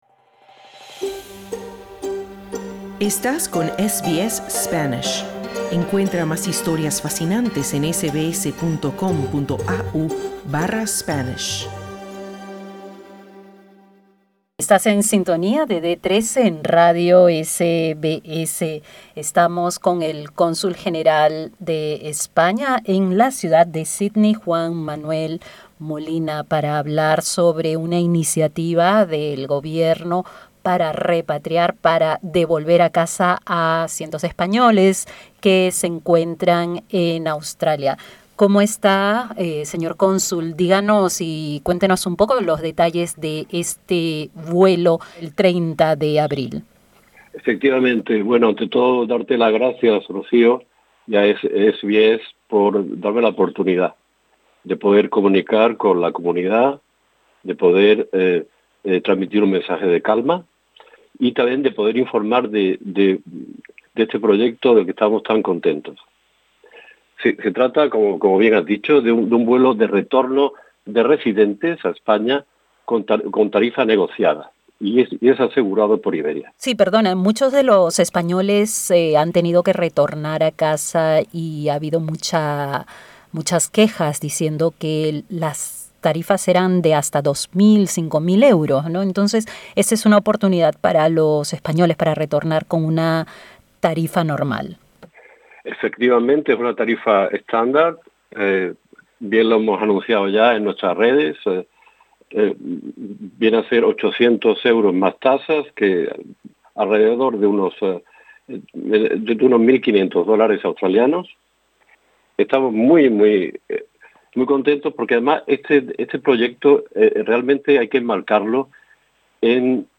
El gobierno español ha facilitado un vuelo de retorno a casa para los residentes y ciudadanos de ese país que se encuentren en Australia. Tiene una tarifa estándar y parte el próximo jueves 30 de abril. El cónsul general de España en Sídney, Juan Manuel Molina, da los detalles a SBS Spanish.